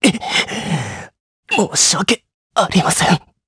Zafir-Vox_Dead_jp.wav